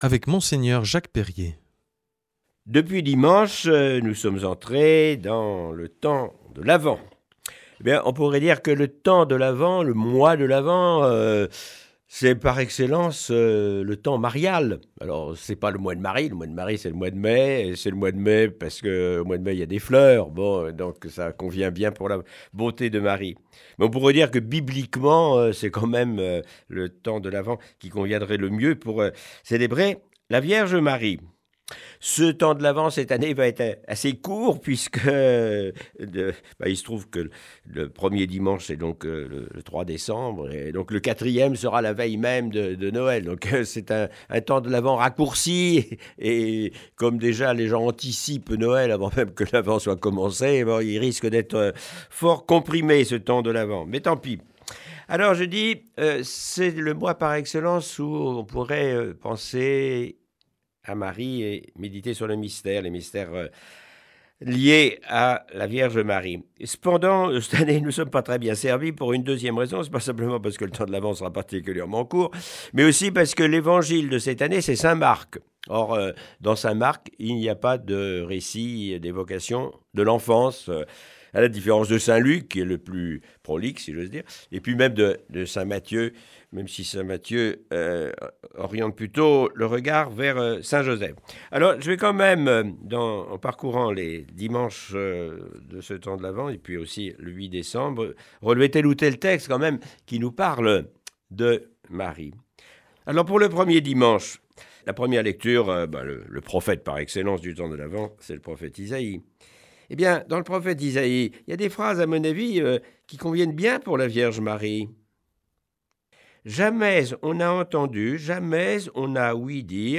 Aujourd’hui avec Mgr Jacques Perrier, évèque émérite de Tarbes et Lourdes.